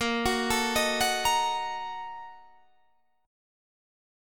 A#M7sus4#5 Chord
Listen to A#M7sus4#5 strummed